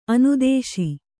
♪ anudēśi